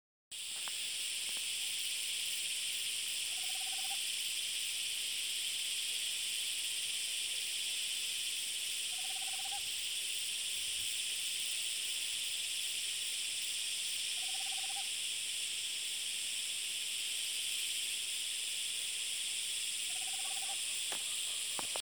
Alilicucú Común (Megascops choliba)
Nombre en inglés: Tropical Screech Owl
Localización detallada: Jardin Botanico
Condición: Silvestre
Certeza: Vocalización Grabada